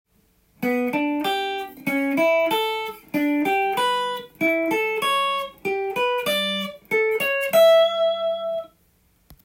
ギターソロでかなり使える【トライアド】
A7が鳴っている時に２つのトライアドを行ったり来たりして